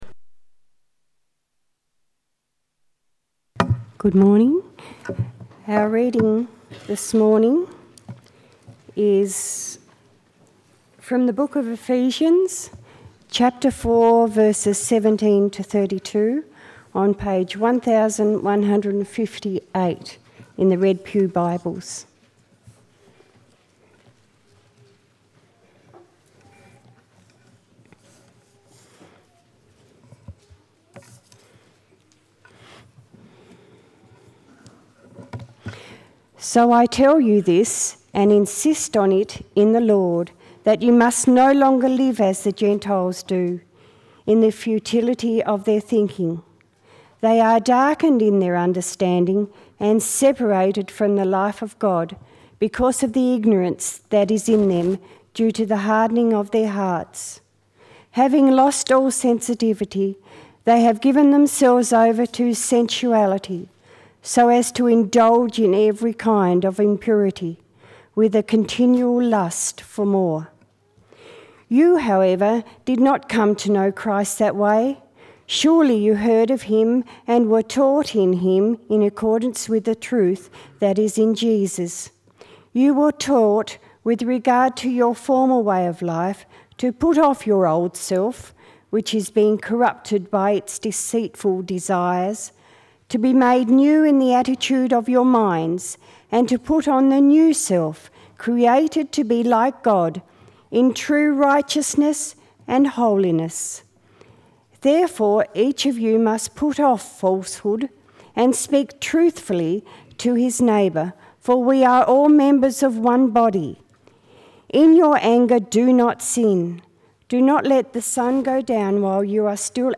30:33 Sermon